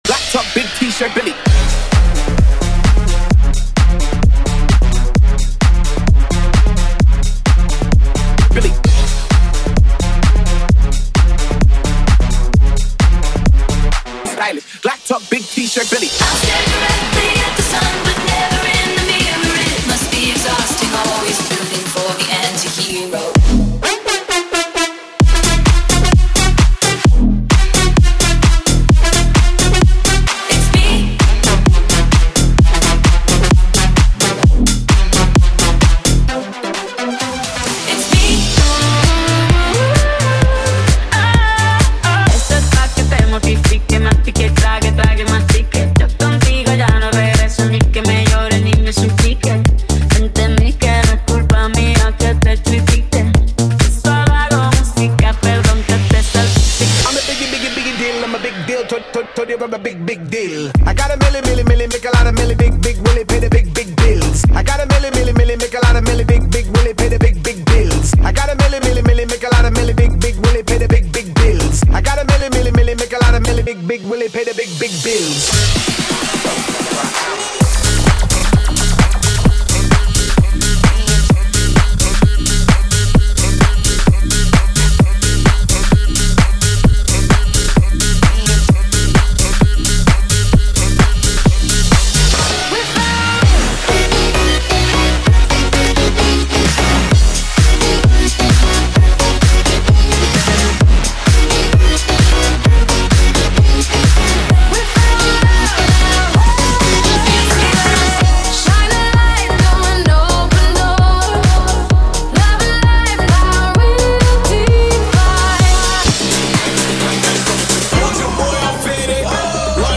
BPM: 130|140|150 (58:00)
Format: 32COUNT
Megamix of Radio, Classic, Club Bangers!